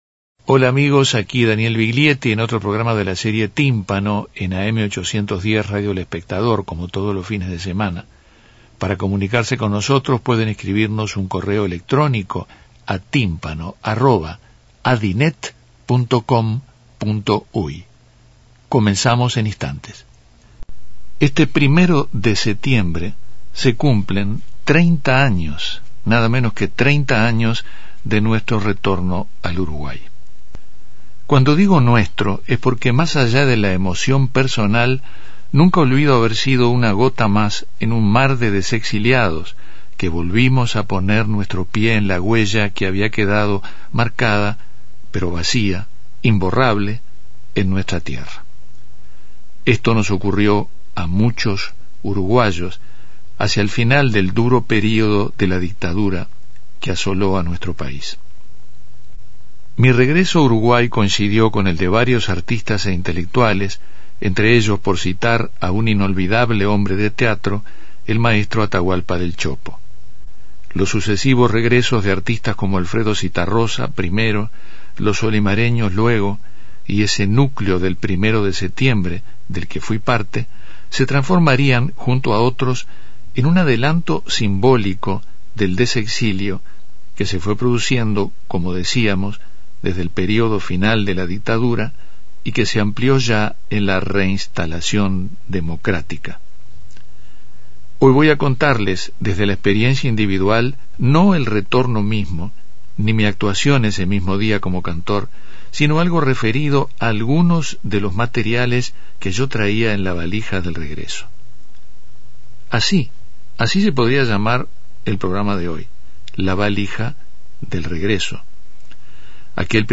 A 30 años del regreso a nuestro país en setiembre de 1984 , Daniel Viglietti comparte fragmentos de entrevistas realizadas durante los años de su exilio, a Julio Cortázar, Chico Buarque y Joan Manuel Serrat entre otros.